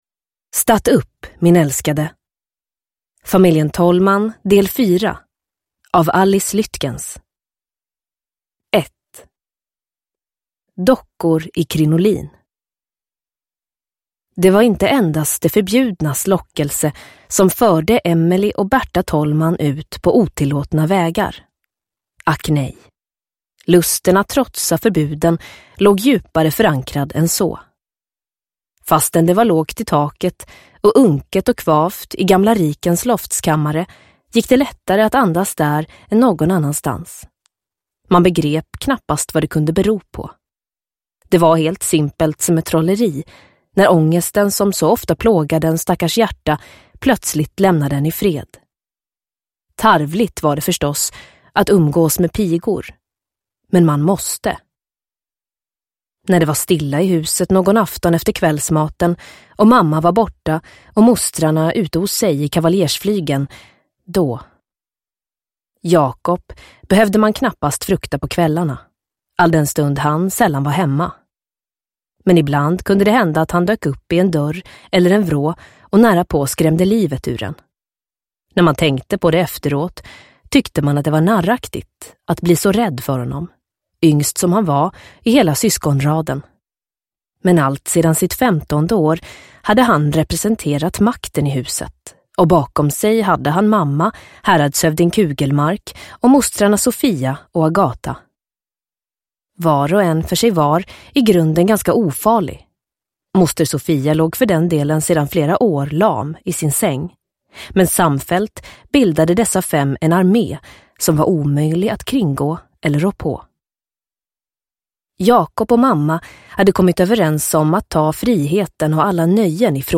Statt upp min älskade – Ljudbok – Laddas ner